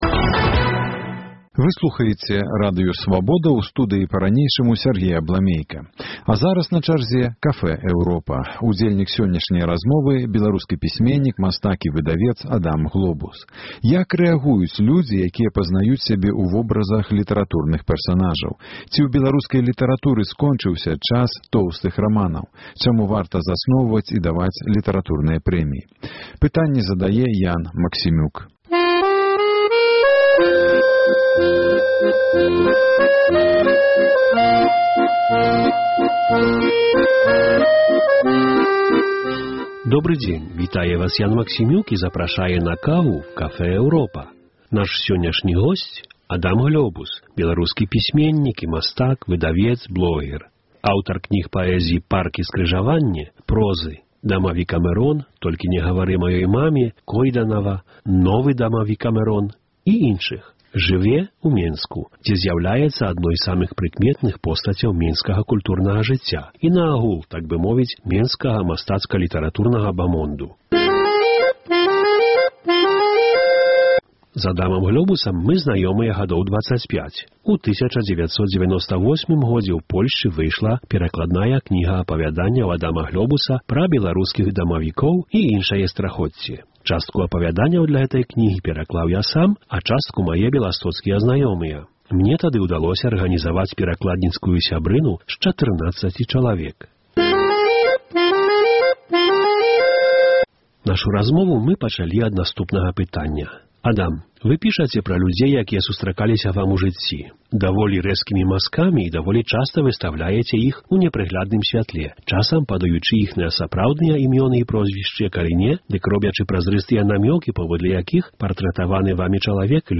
Удзельнік сёньняшняй размовы ў Cafe Europa – беларускі пісьменьнік, мастак і выдавец Адам Глобус.